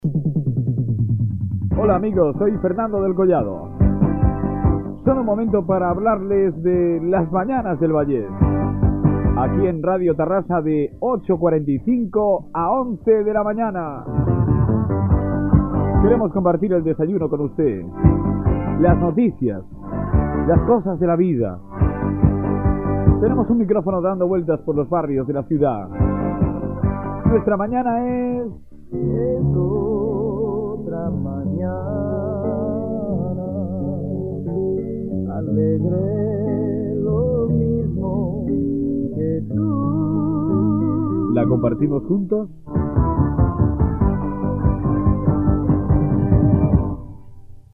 Salutació, identificació i presentació del programa.
Info-entreteniment